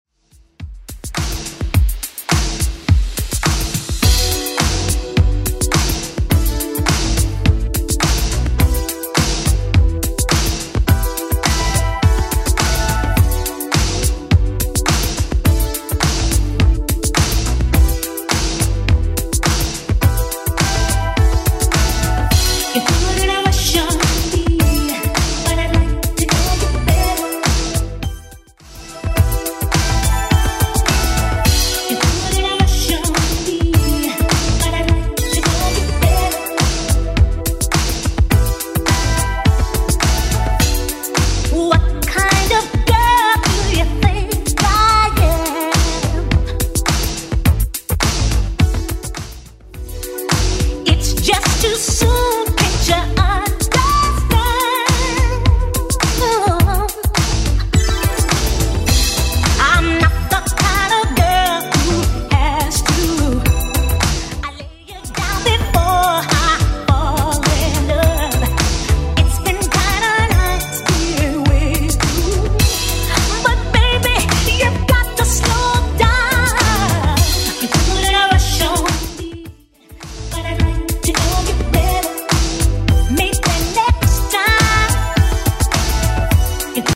Genre: 2000's